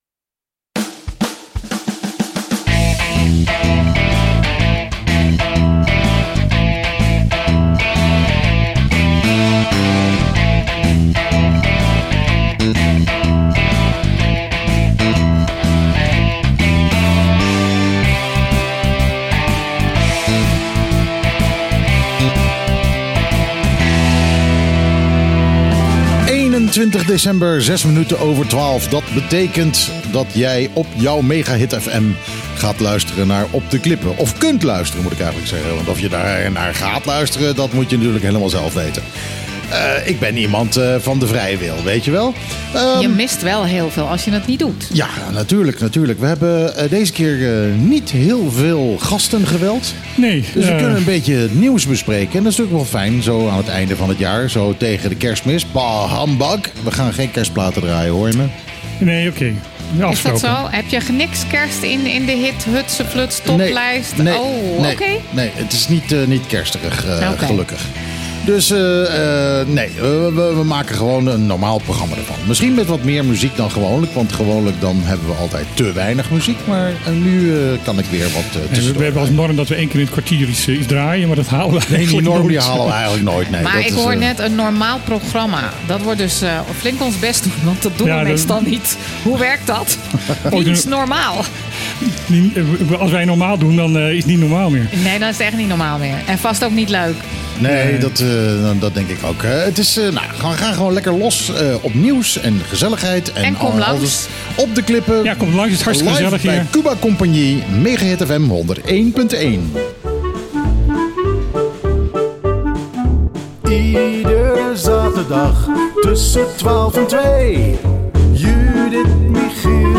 Deze week was het bij het enige Nederlandse actualiteiten en nieuwsprogramma op de Bonairiaanse radio een stuk minder druk dan de afgelopen weken.